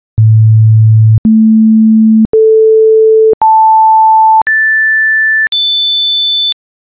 Höre das folgende Beispiel mit den reinen Tönen A a a' a'' a''' a'''' ! Diese (fast) reinen Sinustöne habe ich mit dem Programm "Audacity" erzeugt:
Klangbeispiel
Die meisten Leute empfinden den letzten Ton als zu tief.
oktaven-sinus.mp3